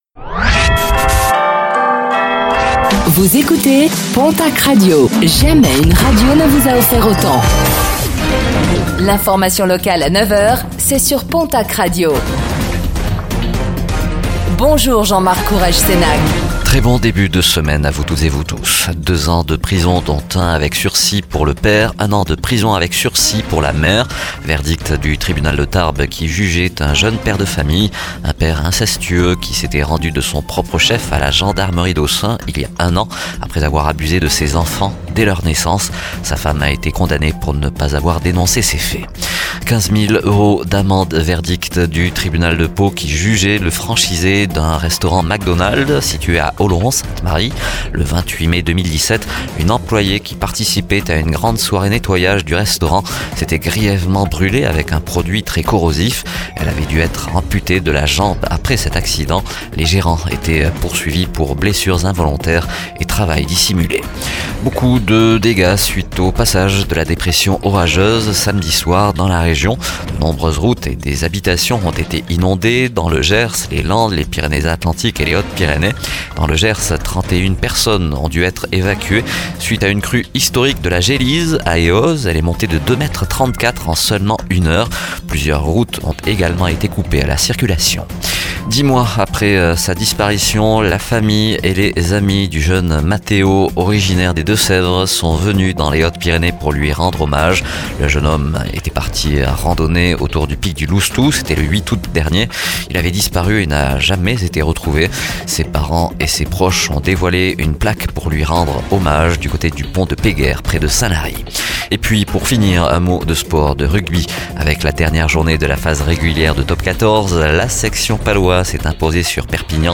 Infos | Lundi 10 juin 2024